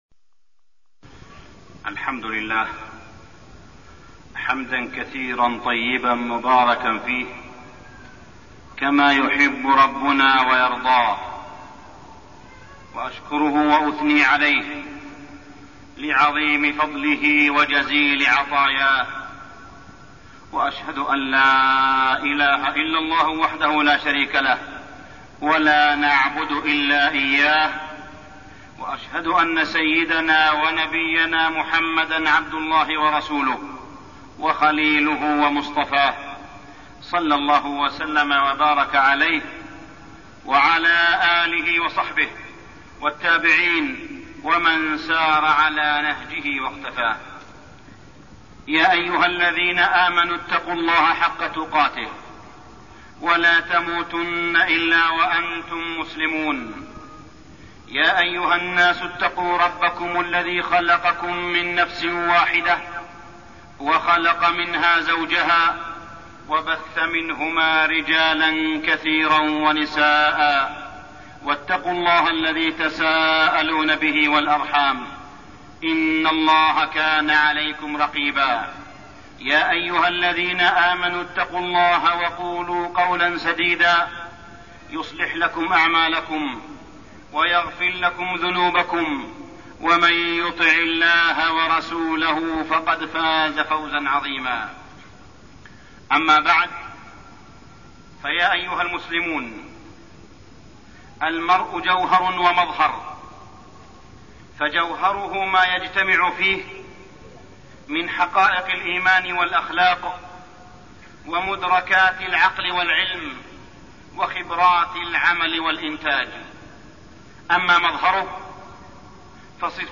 تاريخ النشر ٢١ شعبان ١٤١٦ هـ المكان: المسجد الحرام الشيخ: معالي الشيخ أ.د. صالح بن عبدالله بن حميد معالي الشيخ أ.د. صالح بن عبدالله بن حميد النفاق والمنافقون